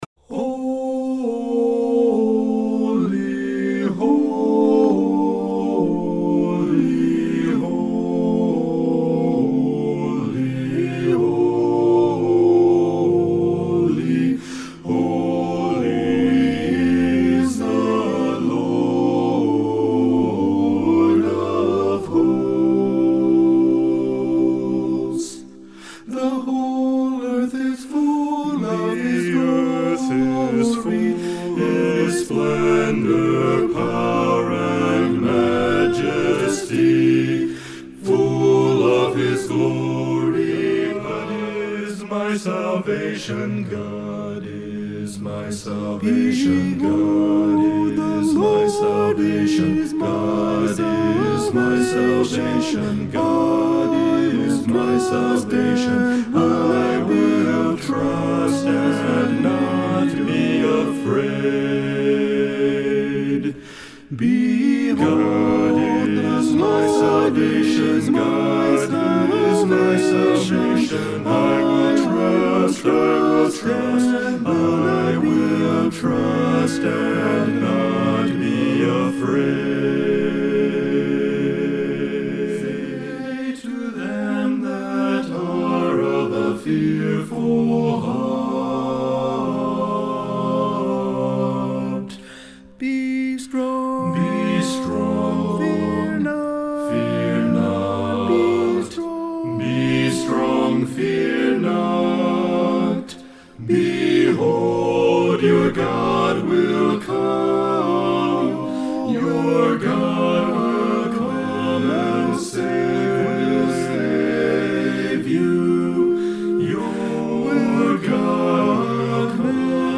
All songs sung acappella.